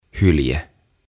Tuhat sanaa suomeksi - Ääntämisohjeet - Sivu 4